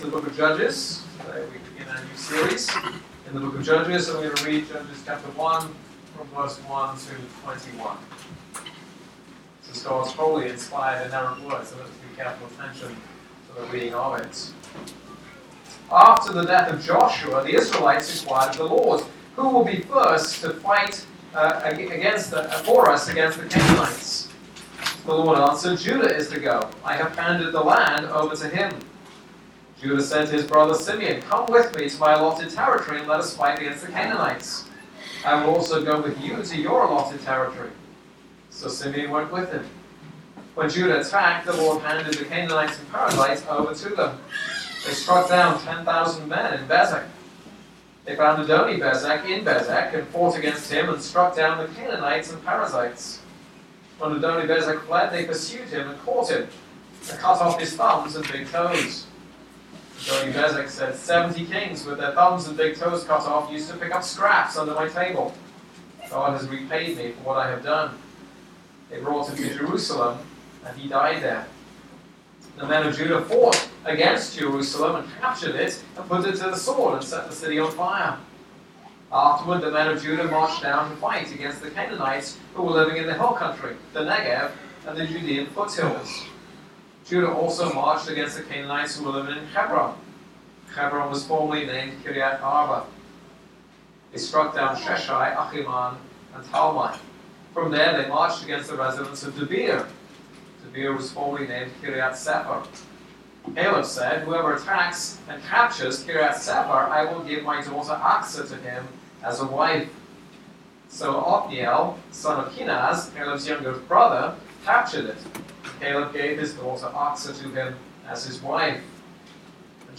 This is a sermon on Judges 1:1-21.